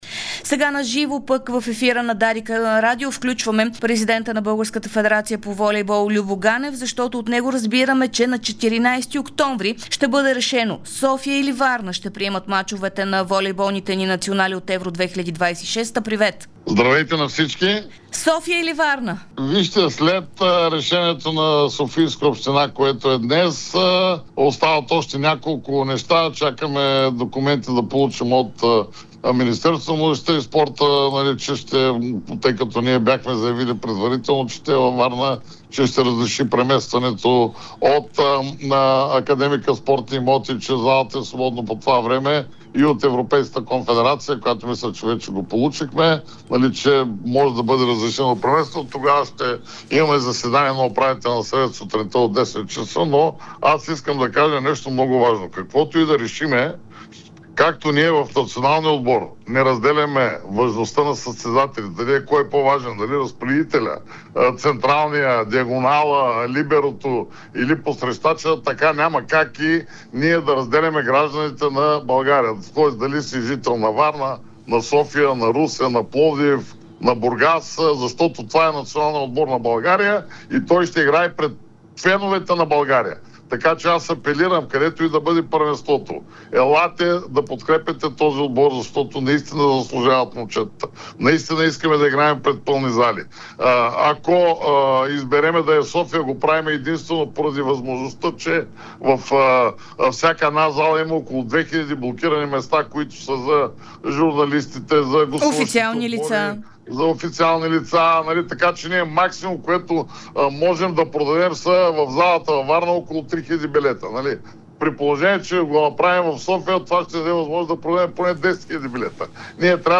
Президентът на Българската федерация по волейбол – Любо Ганев, говори специално пред Дарик радио и Dsport в дните преди да бъде взето решение кой град... (09.10.2025 13:02:32)